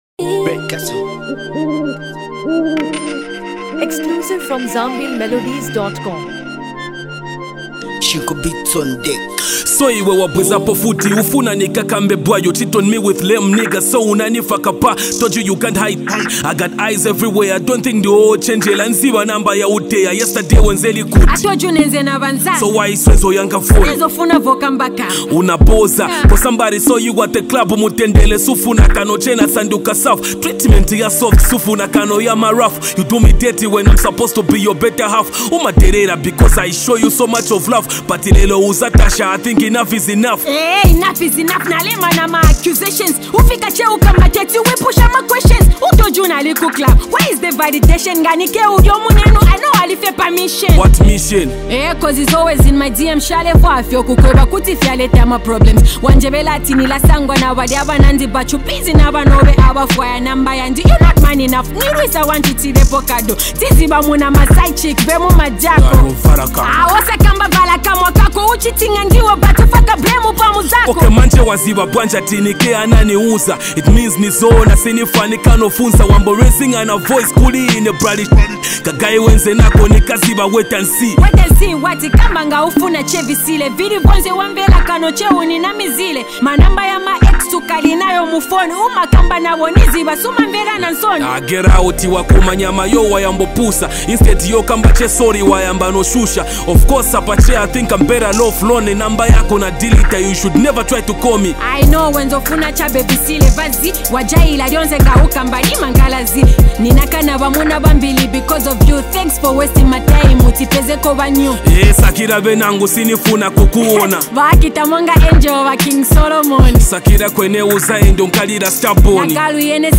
Genre: Afro-beats/RnB